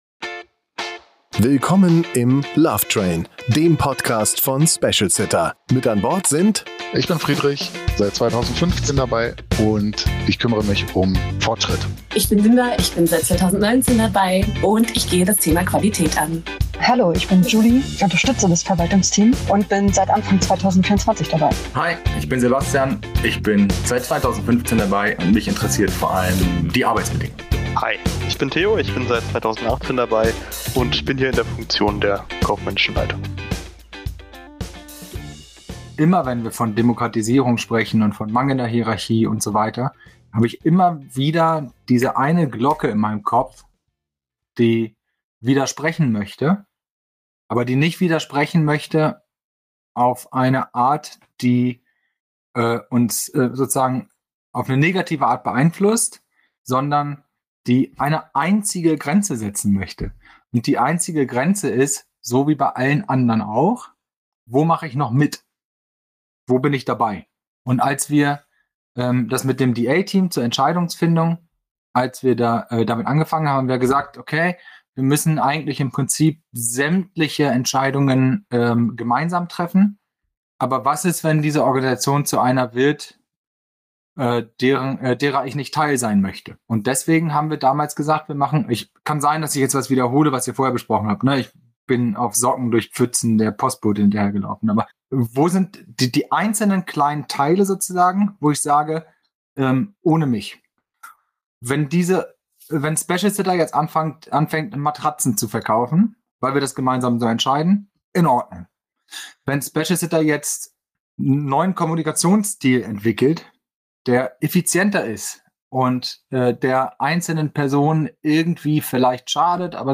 Eine kontroverse Diskussion über Moral, Führung und die Frage, wie viel Mitbestimmung ein Unternehmen verträgt.